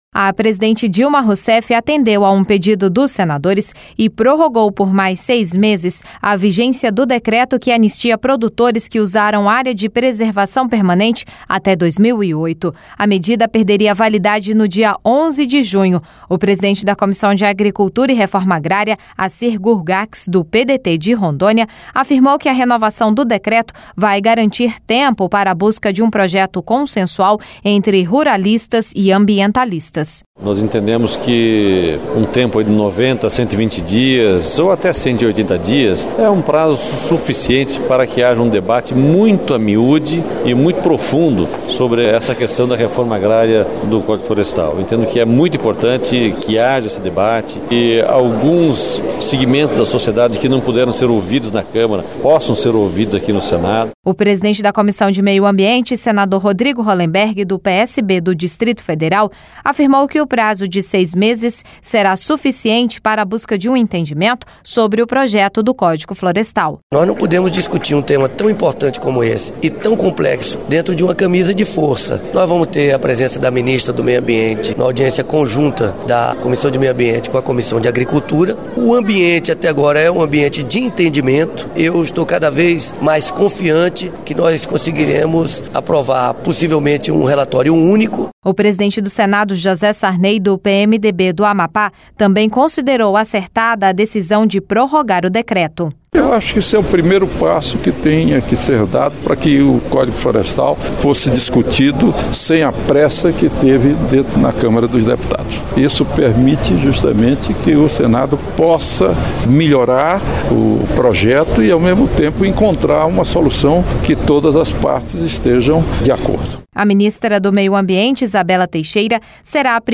REP: O presidente do Senado, José Sarney, do PMDB do Amapá, também considerou acertada a decisão de prorrogar o decreto.